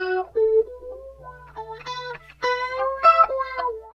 71 GTR 2  -R.wav